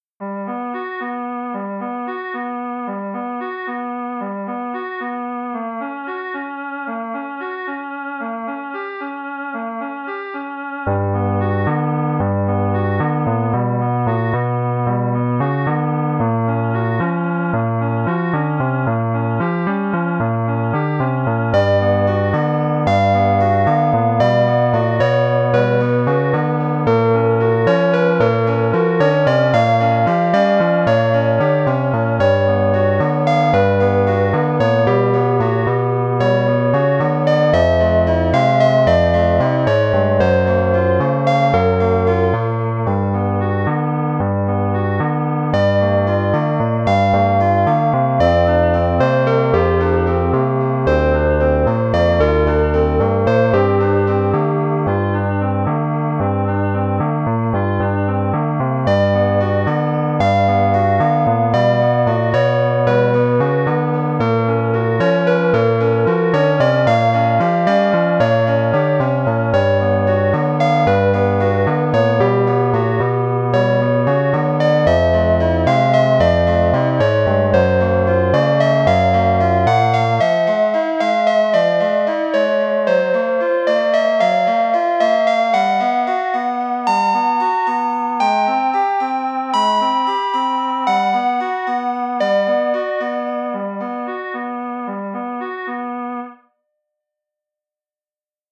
One-hour challenge composition
10edo